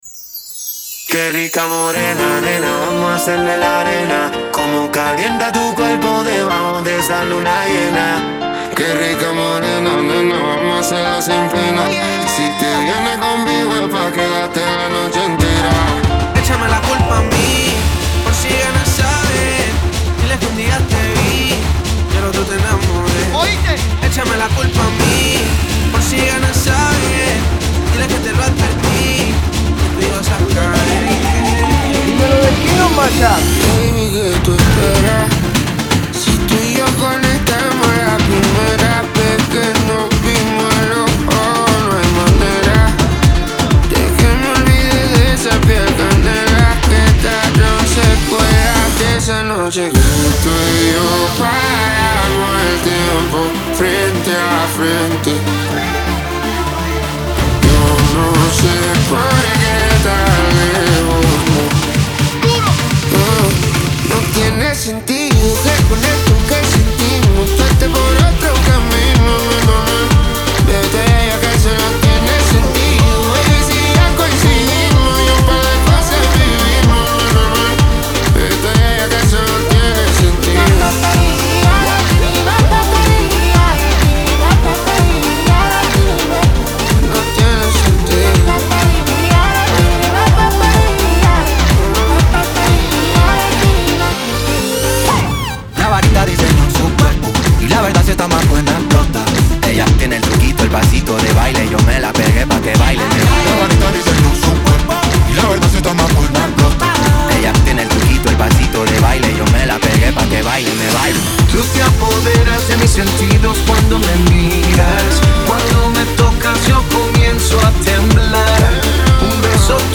Acapella